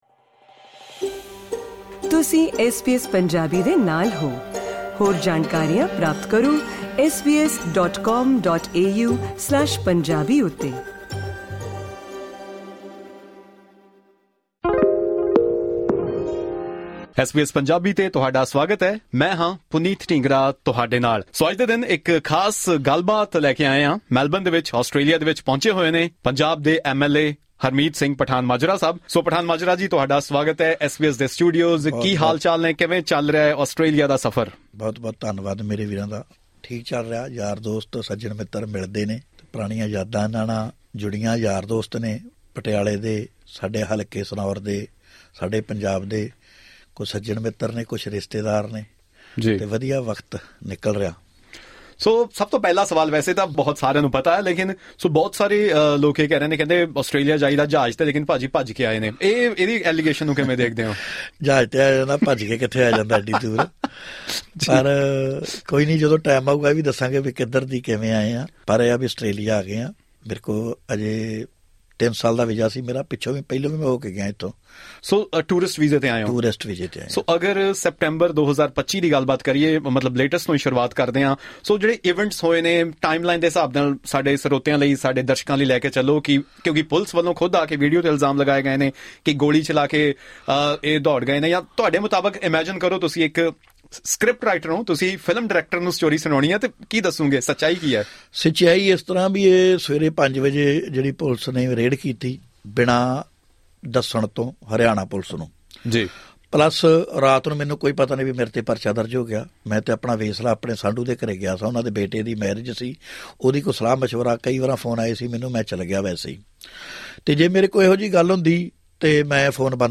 Speaking to SBS Punjabi, he claimed that the charges against him are part of a political conspiracy. He said he came to Australia through legal means and that his visa process is still ongoing. During the interview, Pathanmajra shared the complete timeline of how he managed to avoid police custody and asserted that, in his view, the police did not intend merely to arrest him.